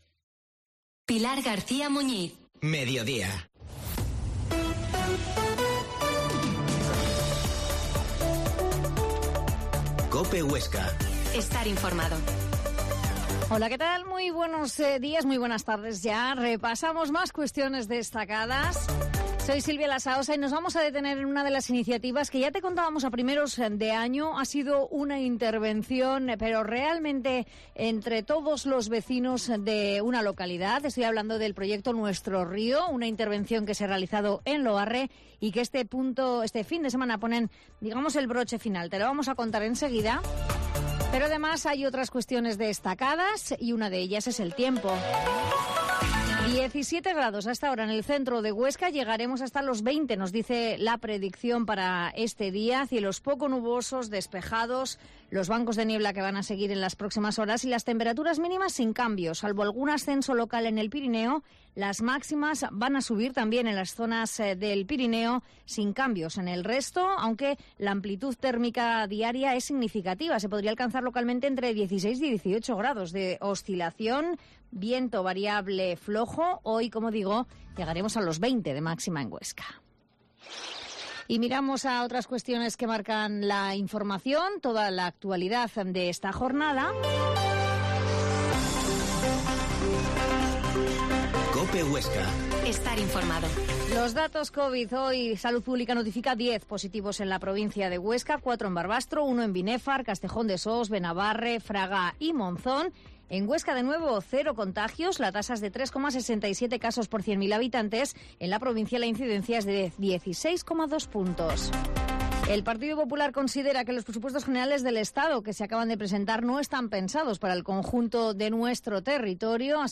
La Mañana en COPE Huesca - Informativo local Mediodía en Cope Huesca 13,50h.